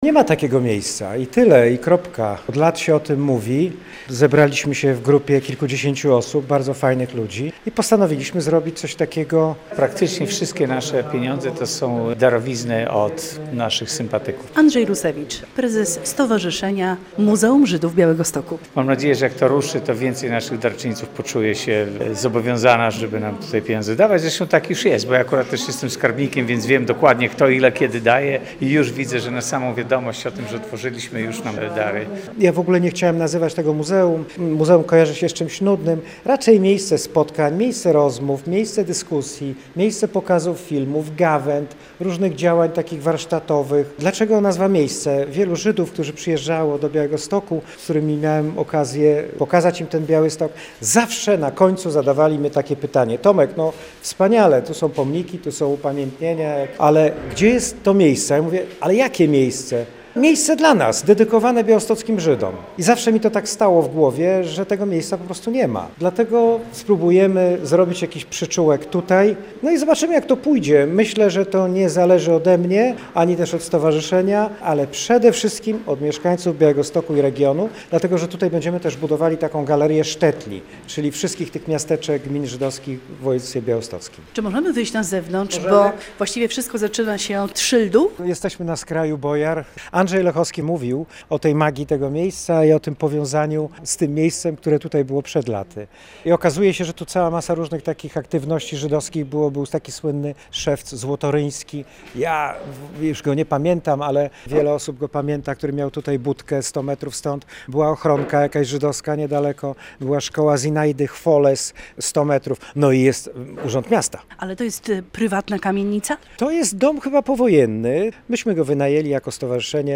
Przy ulicy Modlińskiej 6 w Białymstoku powstało Miejsce - muzeum żydowskie - relacja